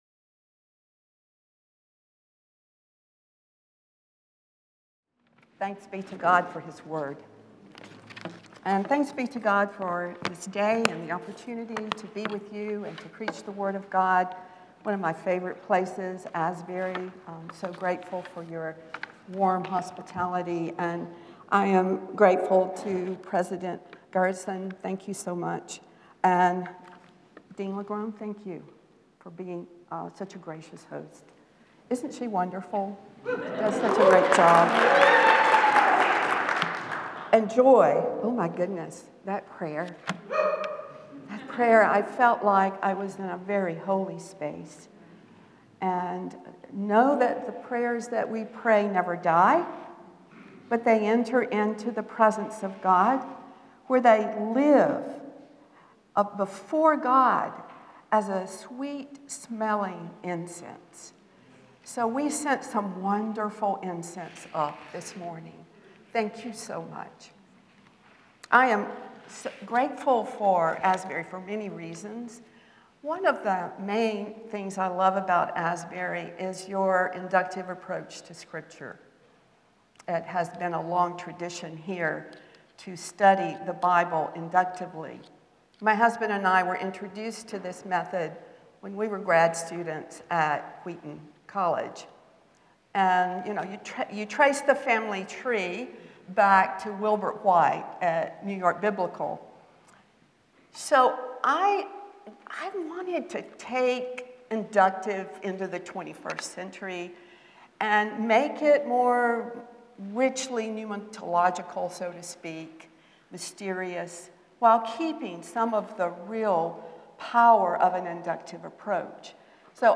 The following service took place on Tuesday, March 4, 2025.